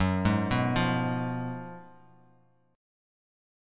F#M7sus2sus4 chord